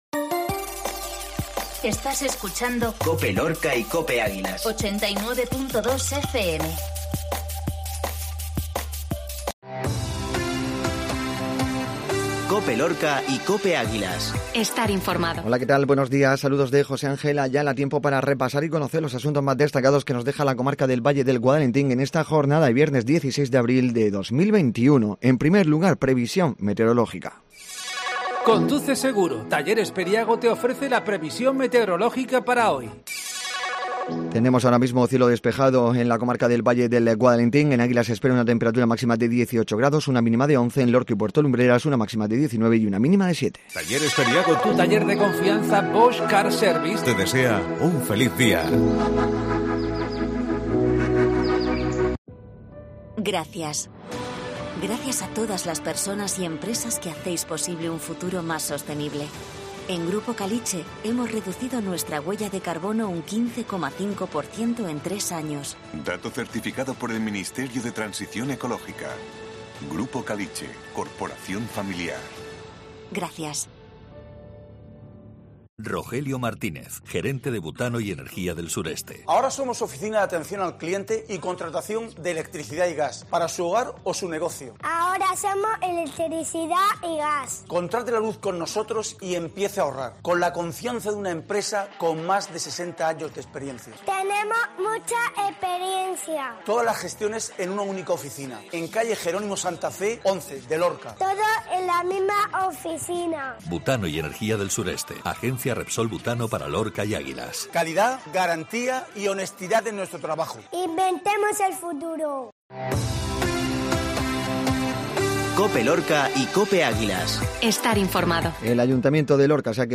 INFORMATIVO MATINAL VIERNES